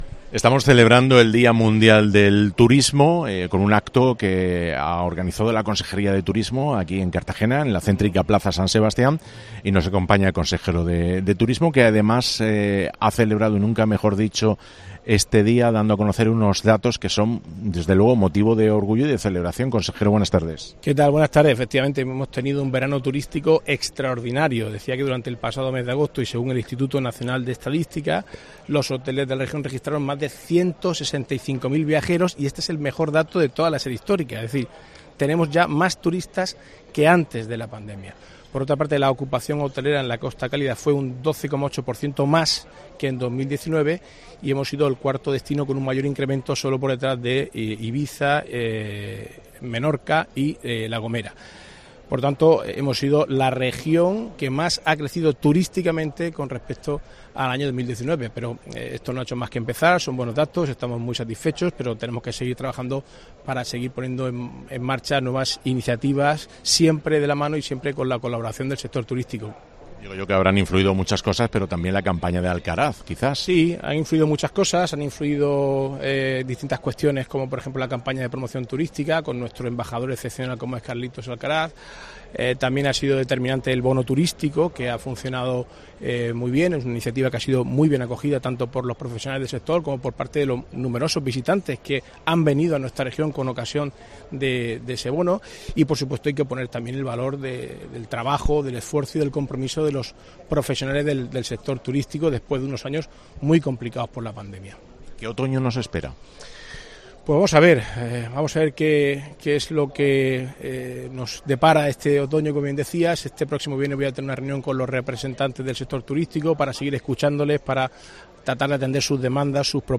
Entrevista al consejero de Turismo